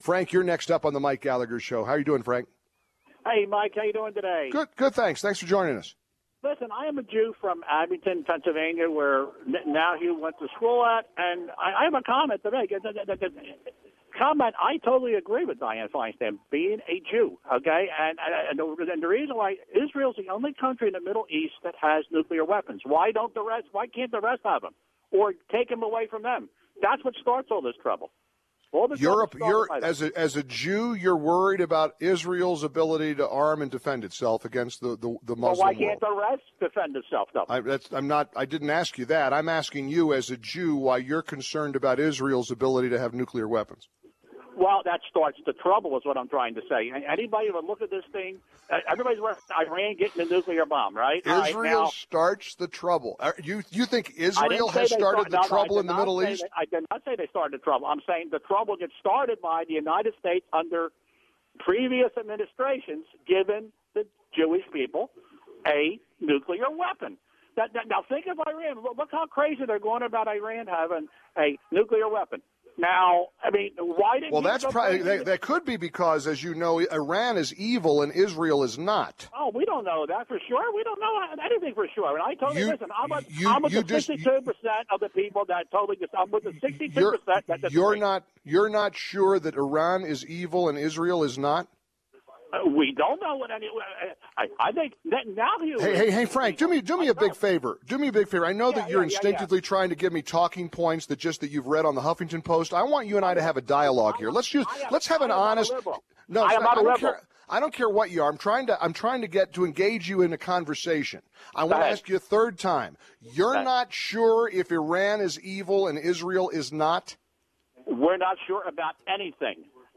Mike argues with a caller who says he’s Jewish over Bibi Netanyahu’s speech before Congress this week. Why do so many Jewish liberals in America refuse to stand with Israel?